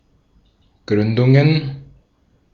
Ääntäminen
Ääntäminen Tuntematon aksentti: IPA: /ˈɡʀʏndʊŋən/ Haettu sana löytyi näillä lähdekielillä: saksa Käännöksiä ei löytynyt valitulle kohdekielelle. Gründungen on sanan Gründung monikko.